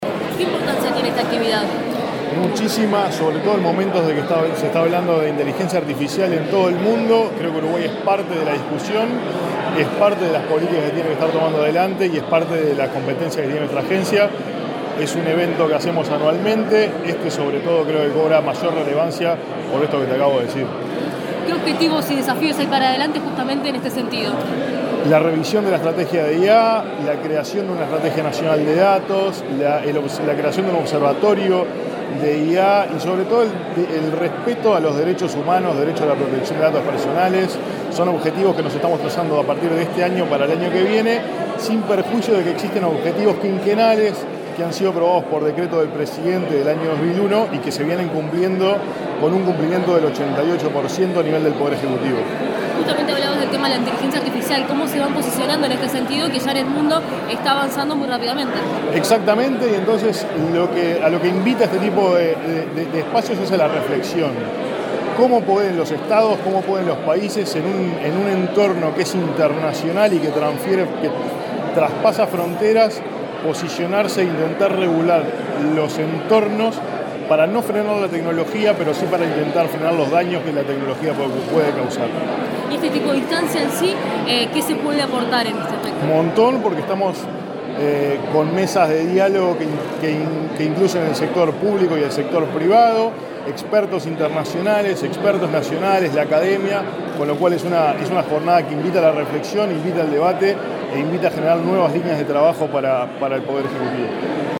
Declaraciones del director ejecutivo de Agesic, Hebert Paguas
Este jueves 12 en la Torre Ejecutiva, el director ejecutivo de la Agencia de Gobierno Electrónico y Sociedad de la Información y del Conocimiento